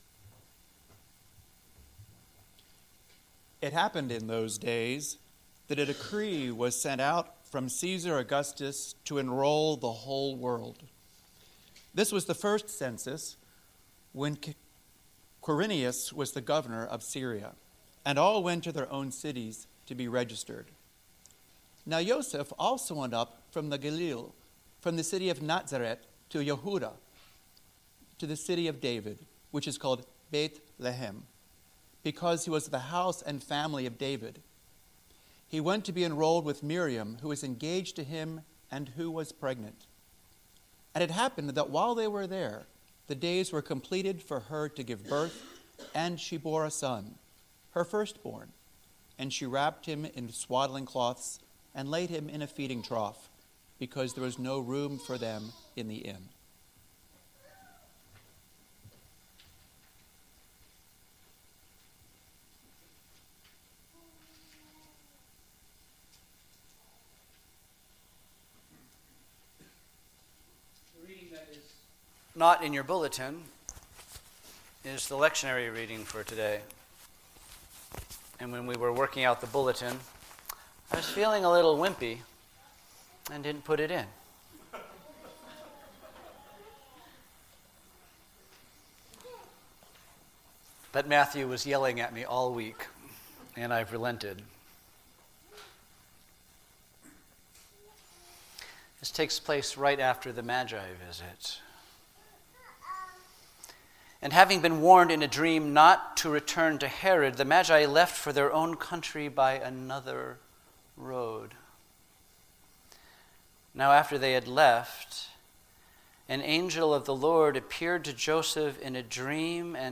Sermons Back to Business as Usual?